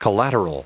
900_collateral.ogg